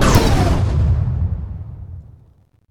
youhit1.ogg